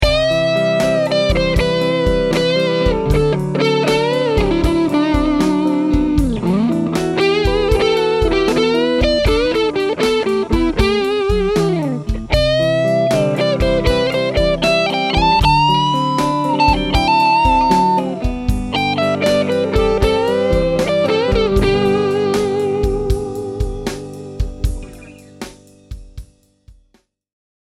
Talk about tonal complexity!
Both Pickups
Dirty Lead
I used a Sennheiser e609 instrument mic, and recorded directly into GarageBand with no volume leveling.
both_dirty.mp3